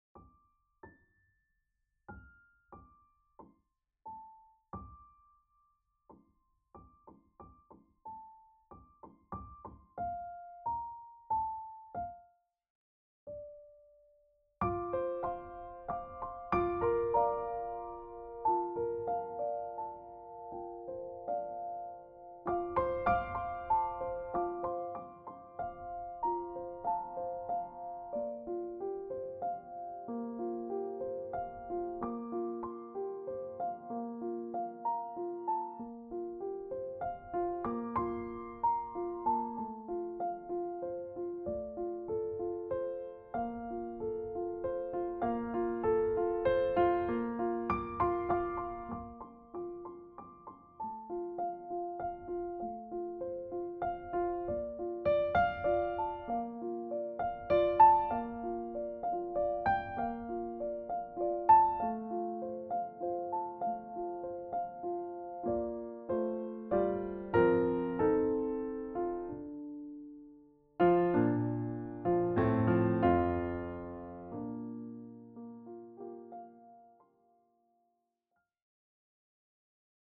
Over this week, I’ve been spontanously listening to some piano work, most of them are based on happiness and love.